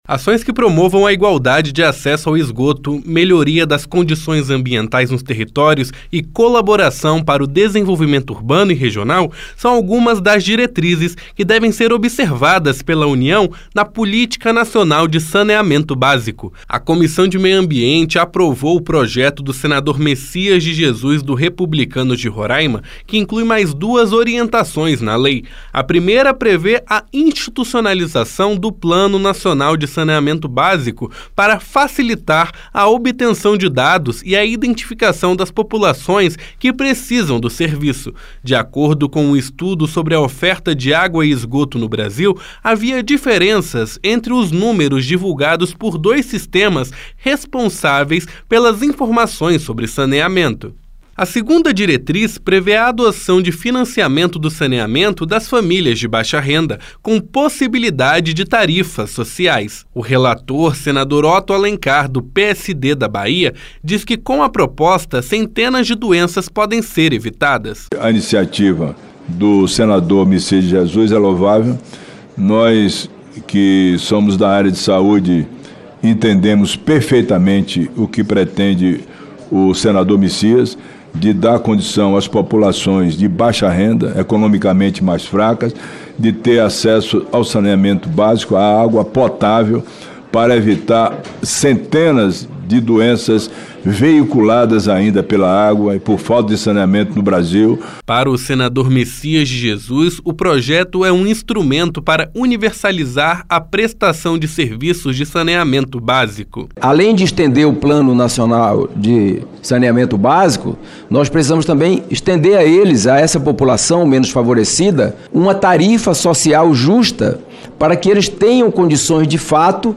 A primeira prevê a institucionalização do plano e a segunda a adoção de uma política de subsídio para tarifa social de água e esgoto. O relator, senador Otto Alencar (PSD-BA), disse que com a proposta, centenas de doenças podem ser evitadas.